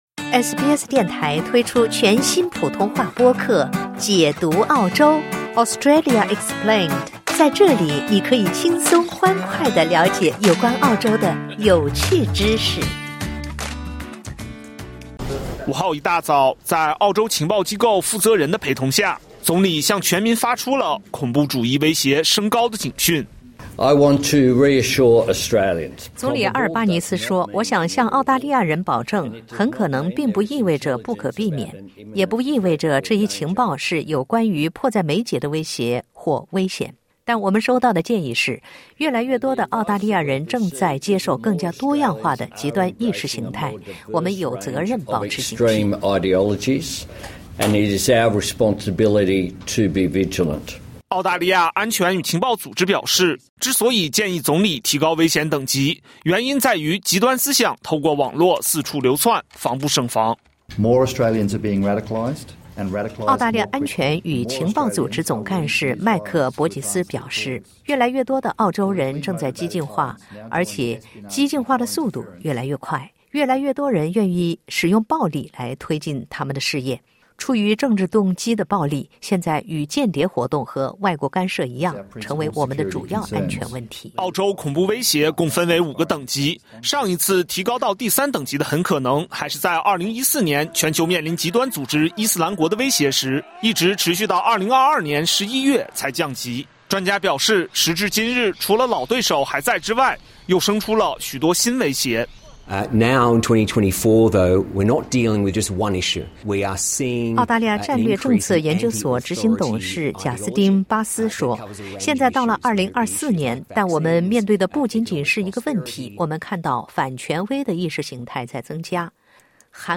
澳大利亚安全与情报组织表示，之所以建议总理提高威胁警告等级，原因在于极端思想通过网络四处蔓延，防不胜防。点击音频收听报道。